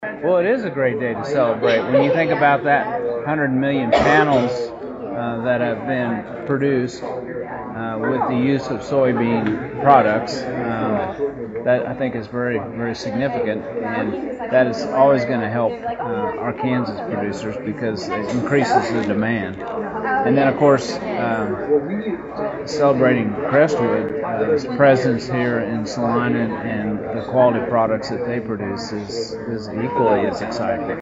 Kansas Secretary of Agriculture Mike Beam told KSAL News it is a win, win for area producers and Crestwood customers.